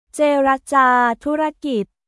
เจรจาธุรกิจ　ジェーラジャー トゥラギット